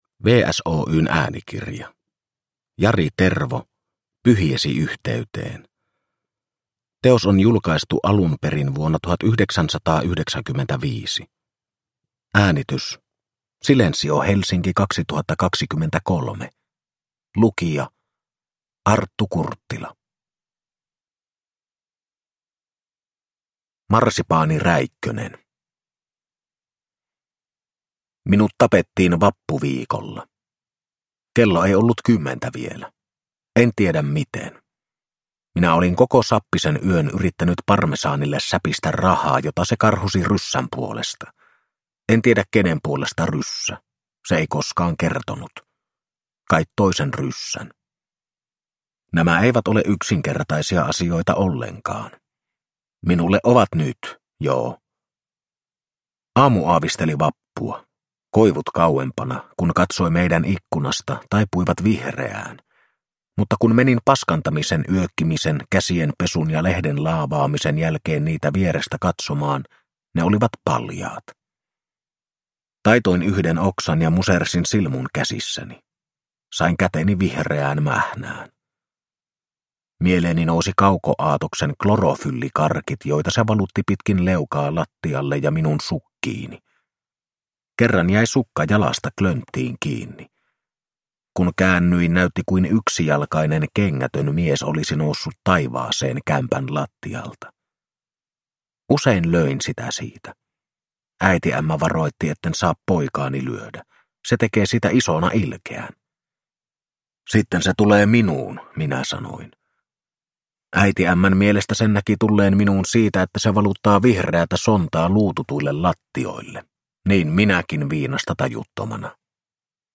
Pyhiesi yhteyteen – Ljudbok